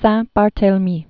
(săɴ-bär-tāl-mē) or Saint Bar·thol·o·mew (sānt bär-thŏlə-my) Familiarly known as Saint Barts (bärts)